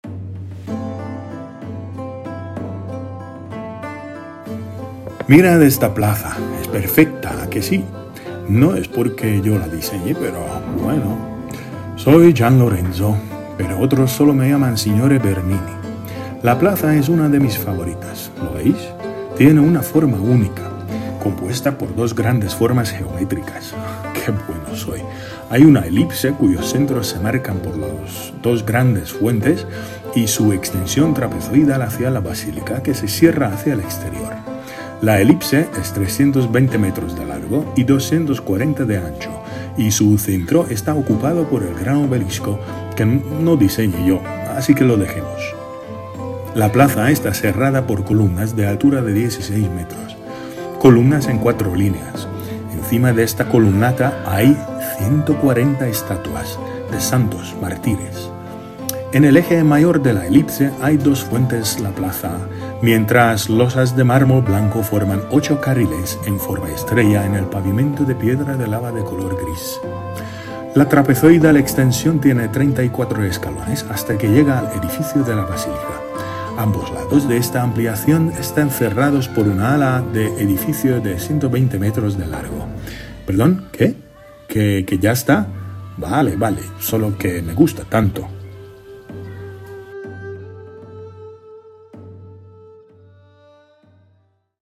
Gian Lorenzo Bernini habla sobre su plaza favorita